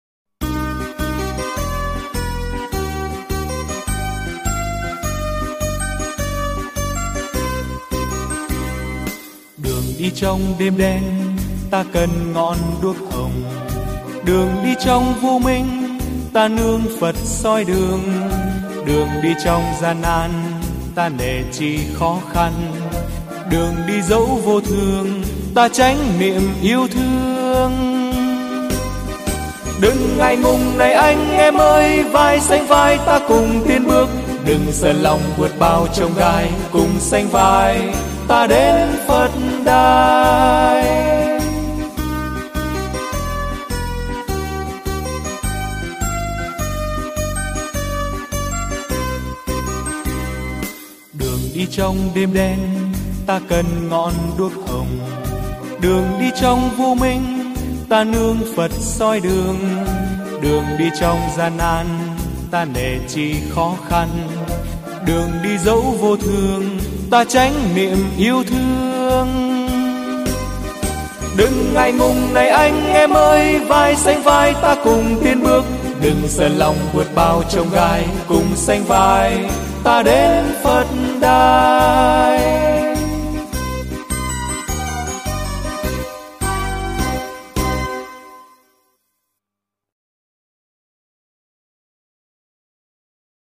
Tân Nhạc